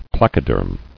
[pla·co·derm]